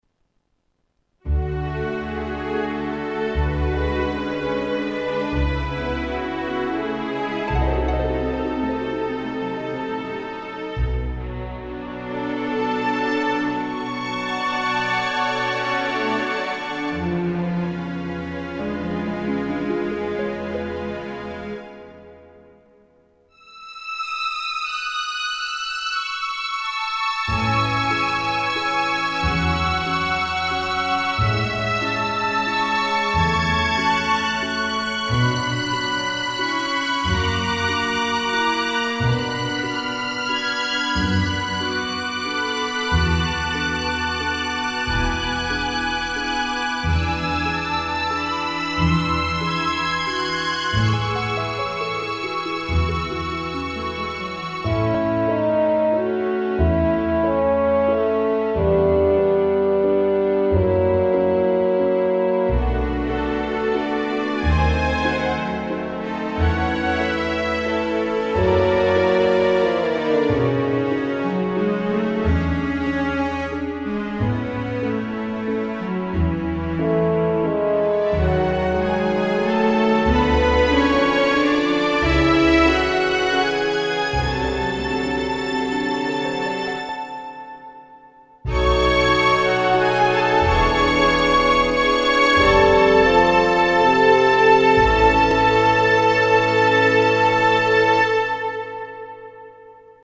String ensemble Sound expansion
Rackmount unit based on orchestral strings samples from the nineties.
Internal waveforms are mainly strings, like cello, violin, ensemble but also various orchestral instruments e.g harp, timpani, harpsichord or tambourine.